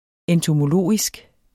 Udtale [ εntomoˈloˀisg ]